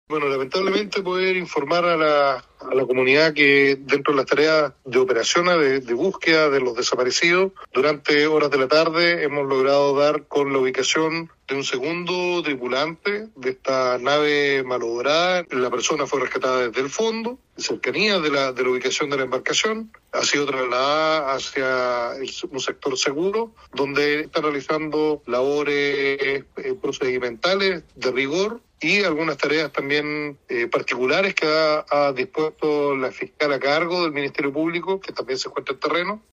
El Gobernador Marítimo de Puerto Montt, Mario Besoain, entregó esta información, recalcando que de esta manera se contabilizan dos personas fallecidas, dos sobrevivientes y cuatro tripulantes hasta este minuto desaparecidas.
GOBERNADOR-MARITIMO-PUERTO-MONTT.mp3.mp3